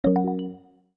new-message-2.mp3